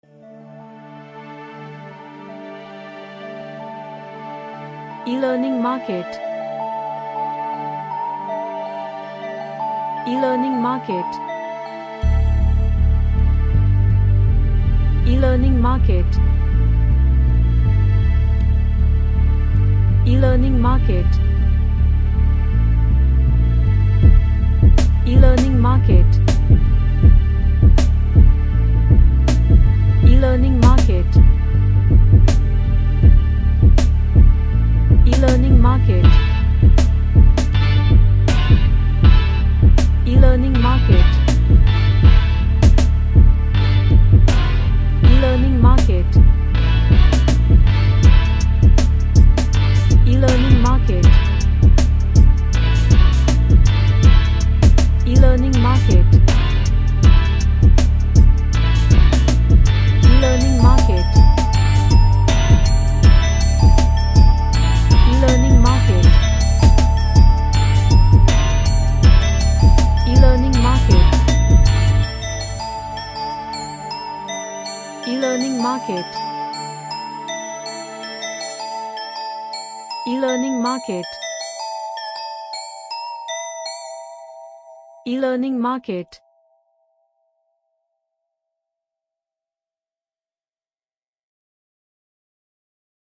Dark / SomberCreepy